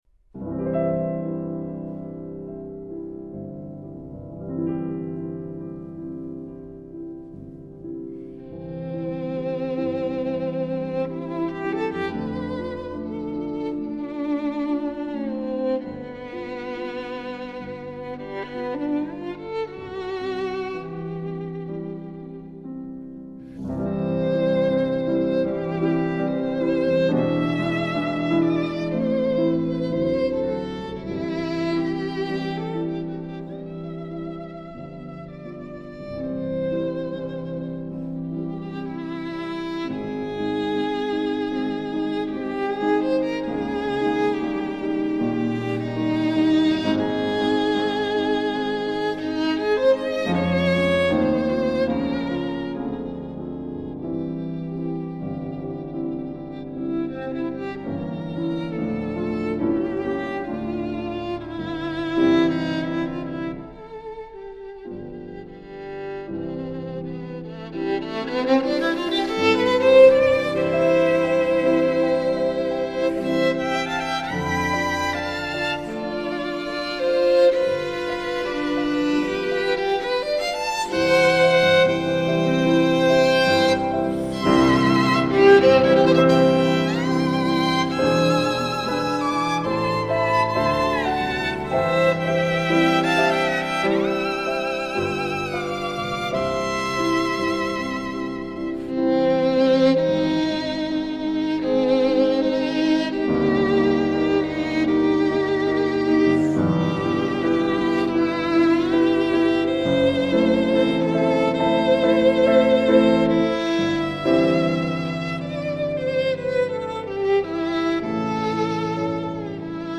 Incontro con il violinista svedese